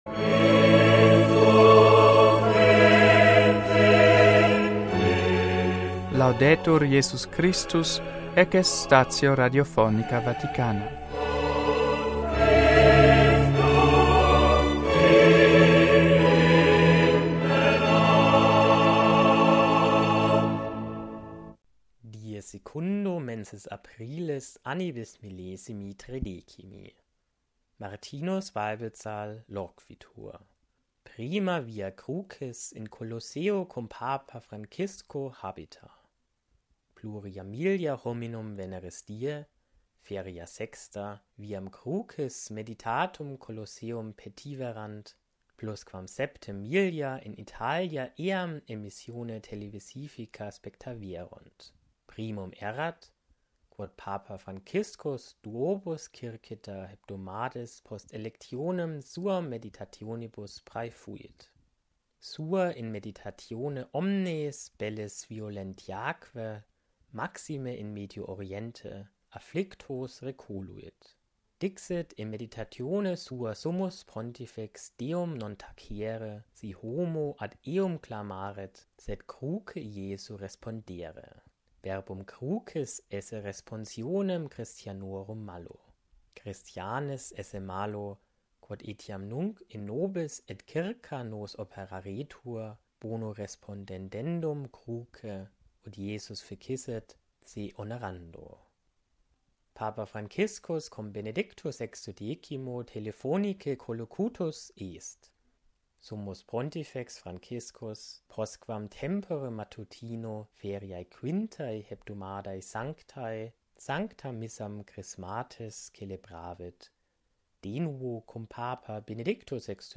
NUNTII STATIONIS RADIOPHONICAE VATICANAE PARTITIONIS GERMANICAE IN LINGUAM LATINAM VERSI